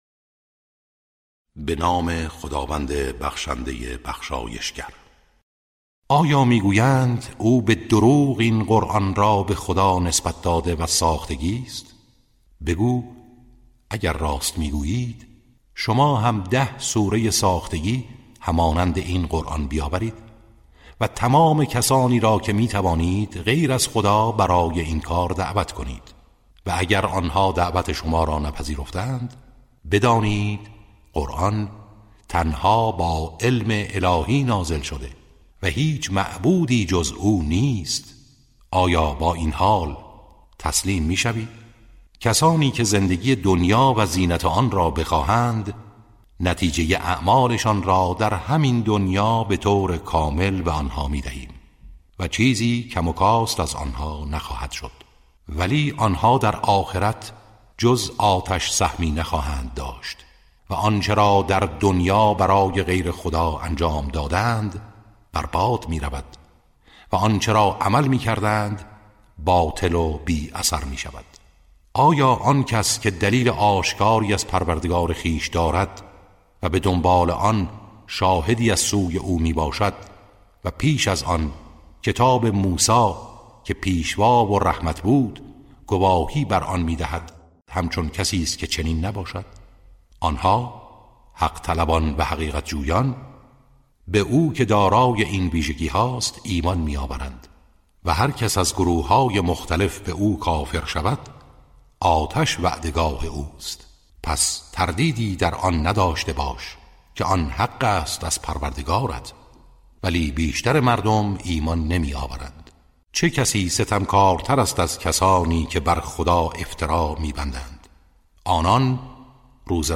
ترتیل صفحه ۲۲۳ سوره مبارکه هود(جزء دوازدهم)
ترتیل سوره(هود)